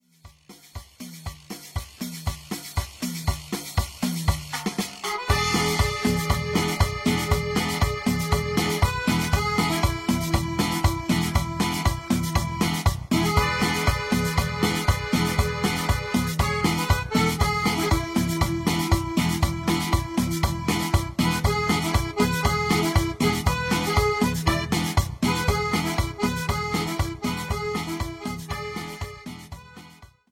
101-Texano-1.mp3